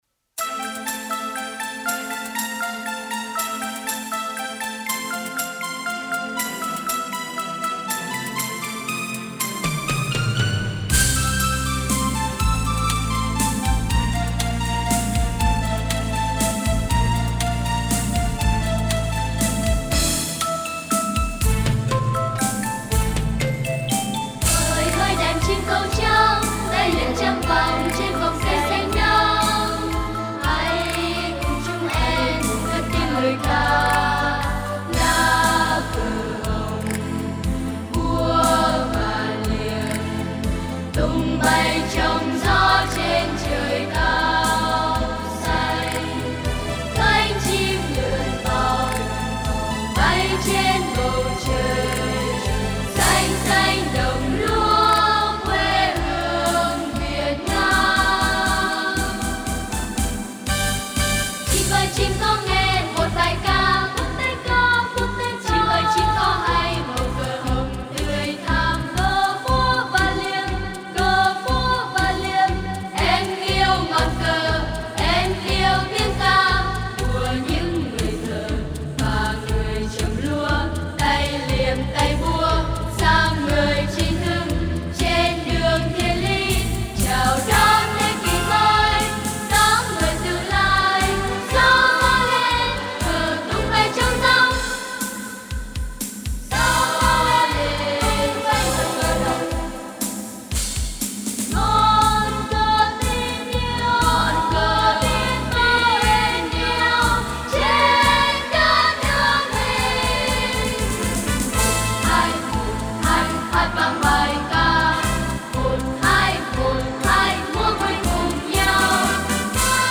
Coro, épico, nuevo typo de música vocal
coro de niños